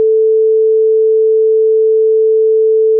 1300AM Radio Interview